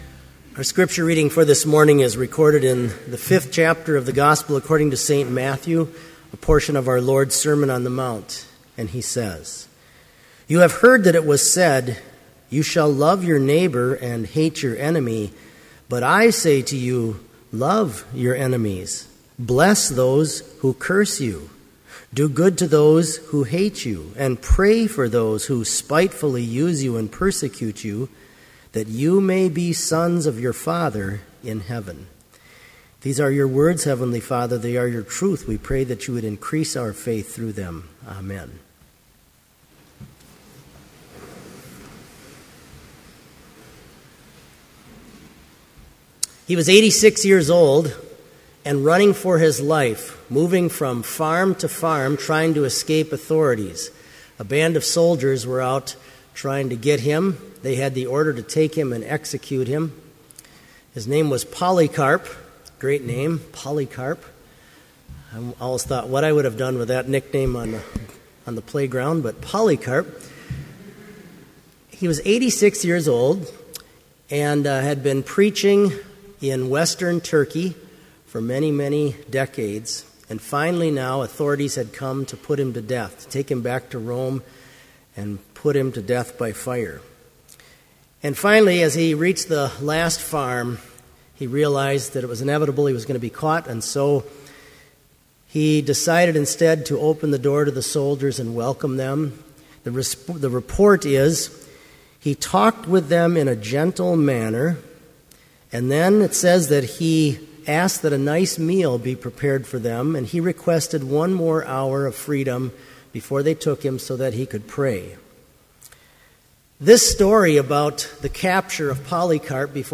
Complete service audio for Chapel - September 4, 2012